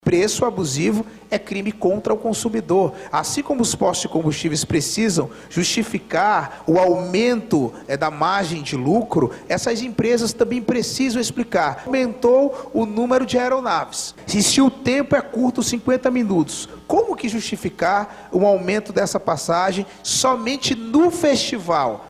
Deputados e deputadas estaduais da Assembleia Legislativa do Amazonas (Aleam) debateram em Sessão Plenária o alto preço das passagens aéreas para Parintins, no mês de junho, que despertou especial atenção dos parlamentares.